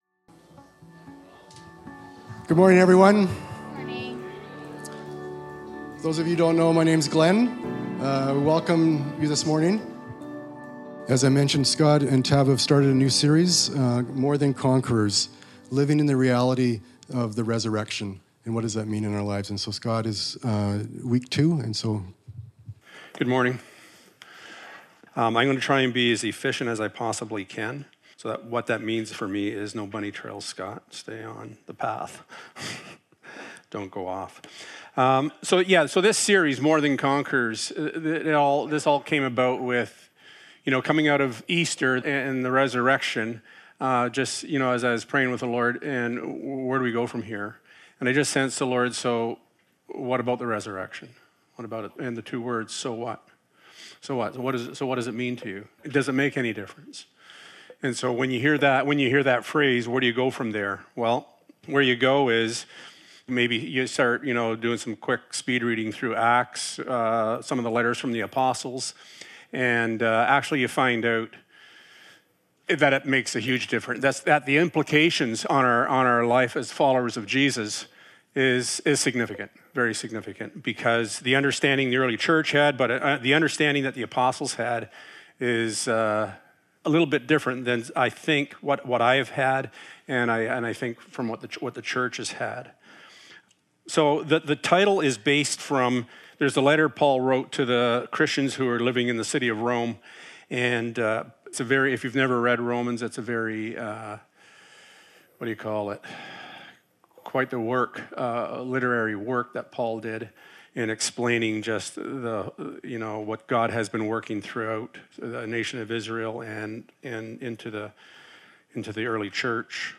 1-7 Service Type: Sunday Morning Jesus died